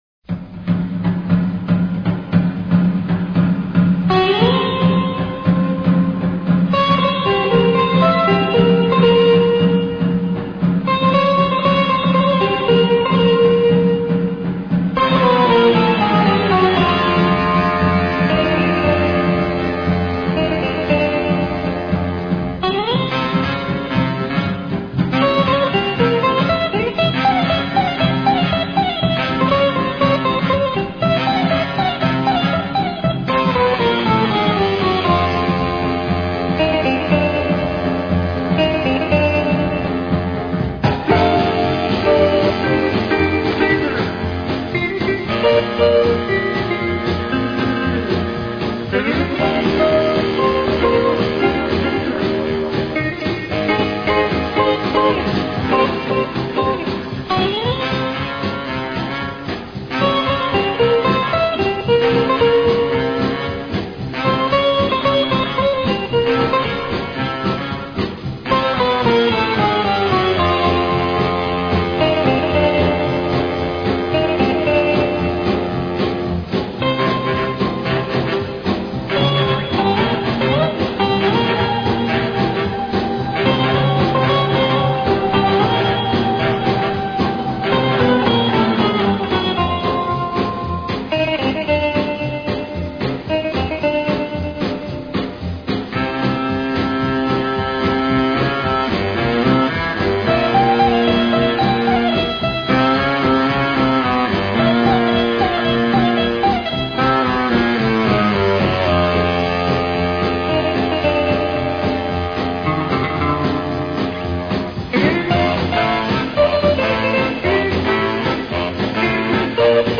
hard-driving